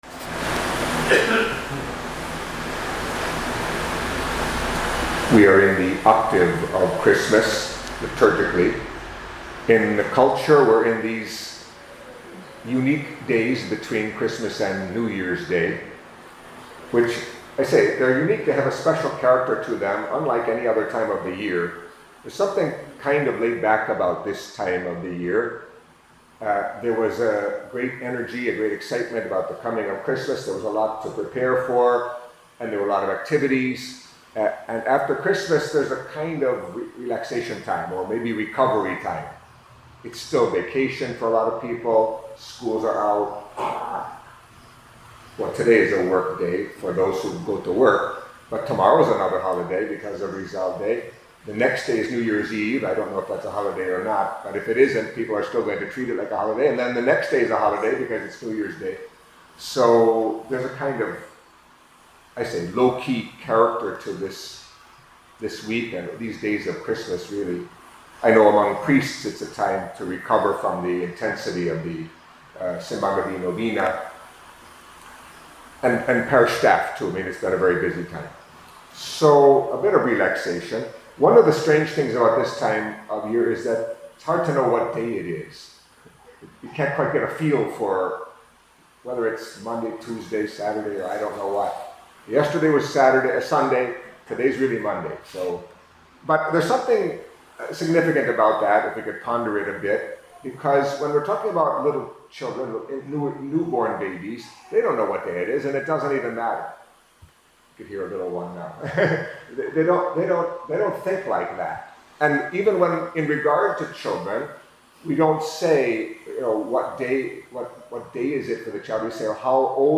Catholic Mass homily for Monday, Fifth Day within the Octave of Christmas